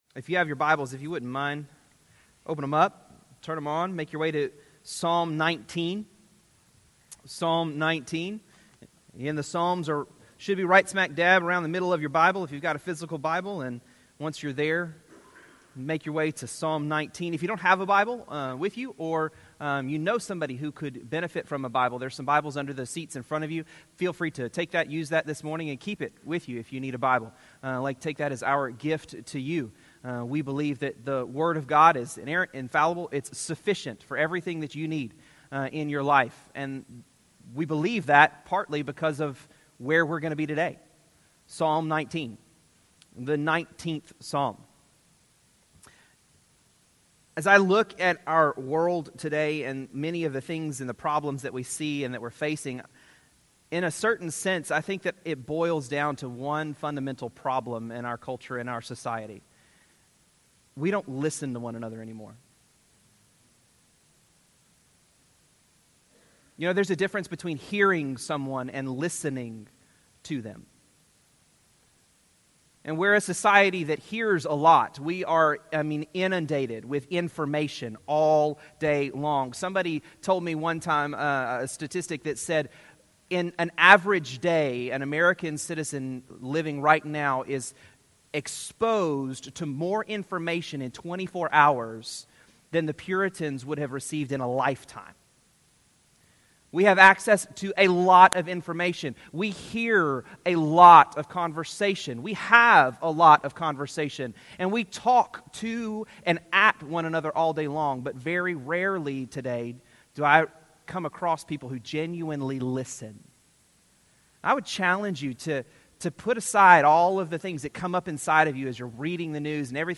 Sermon-2-24-19.mp3